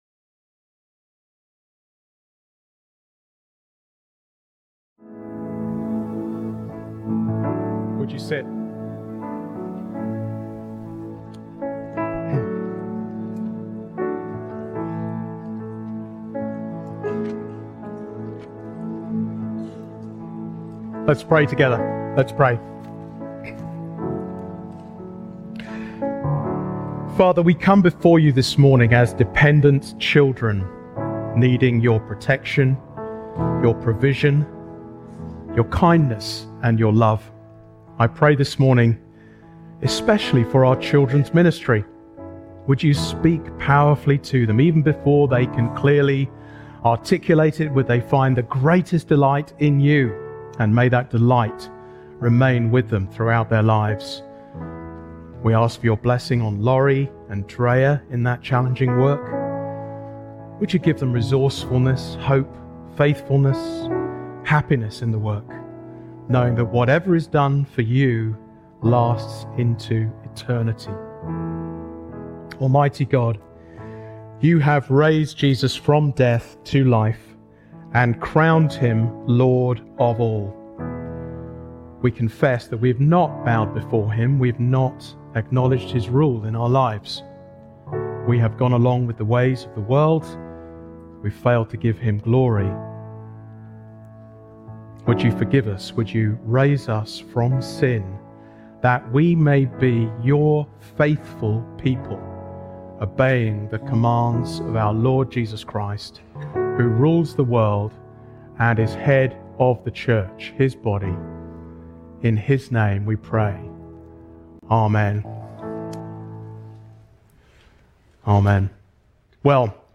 Sermons Christ Community Church: Daytona Beach, FL